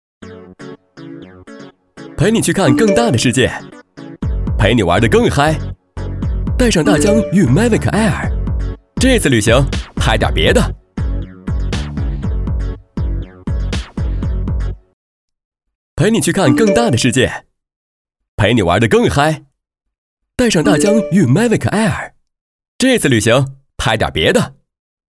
2 男国432_广告_品牌形象_大疆无人机_年轻 男国432
男国432_广告_品牌形象_大疆无人机_年轻.mp3